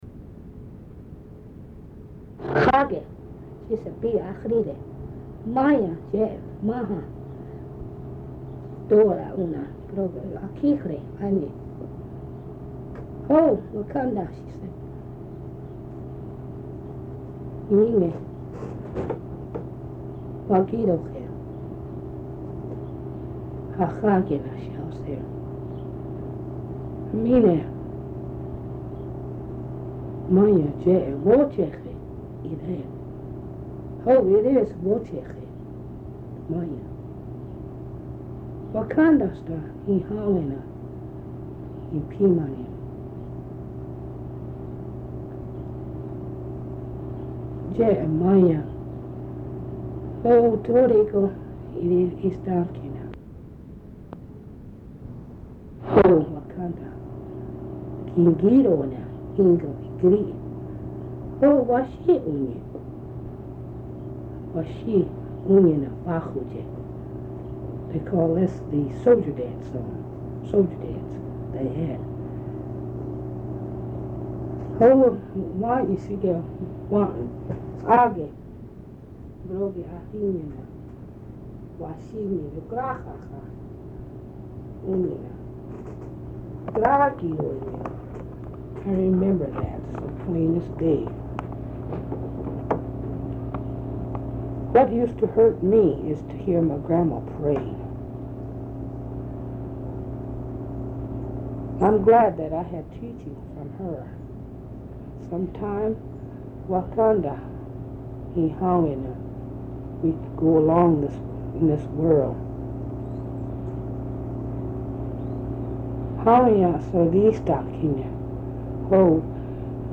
"Reminiscences of Grandmother". Recording of personal reflections of Ioway